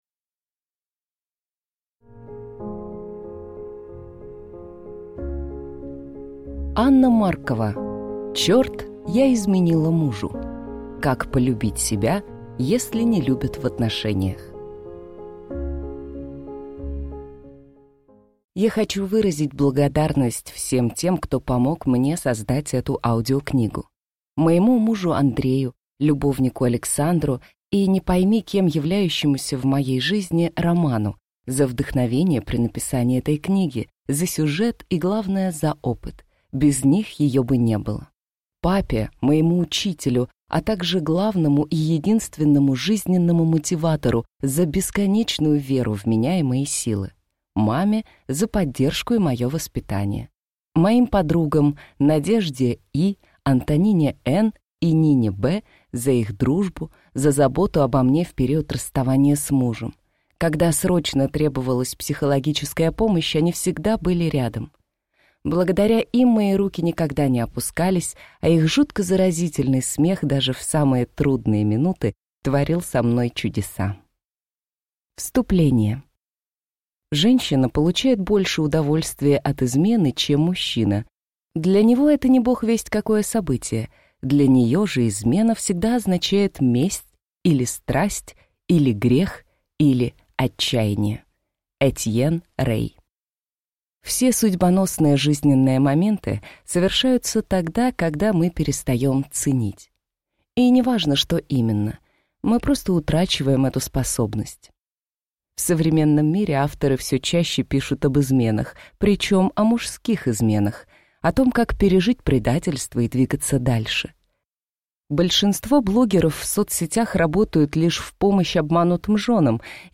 Аудиокнига Чёрт, я изменила мужу. Как полюбить себя, если не любят в отношениях | Библиотека аудиокниг